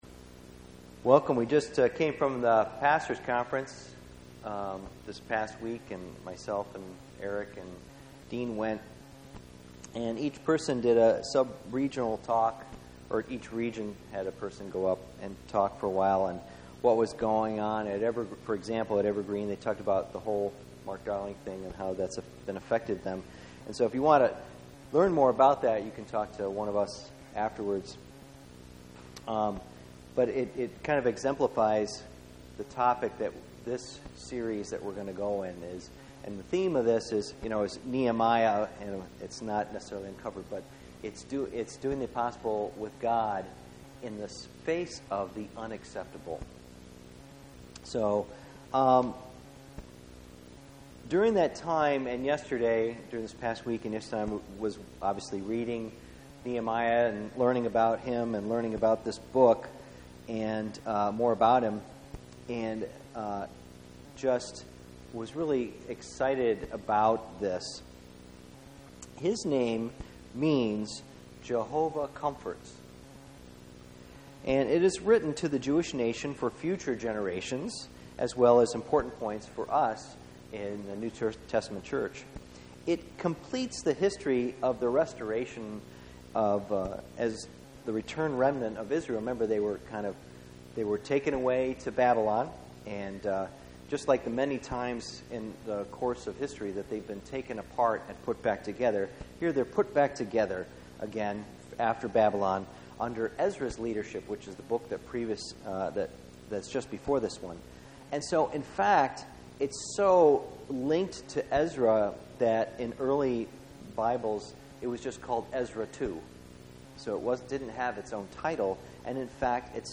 Book of Nehemiah Service Type: Sunday Morning %todo_render% « Stories of Encouragement Nehemiah 2